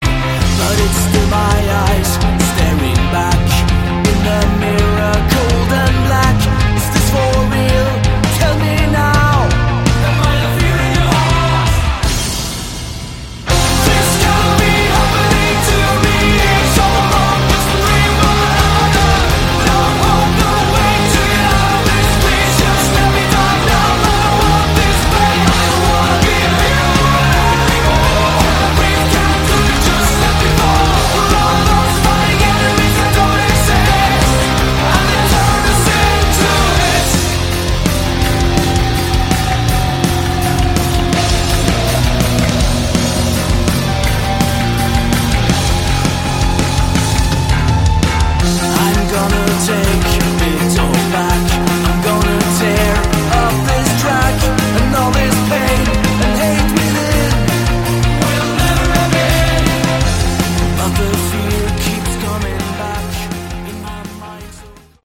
Category: Symphonic Hard Rock
lead vocals, guitars, bass
synthesizers, vocals